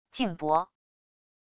镜钹wav音频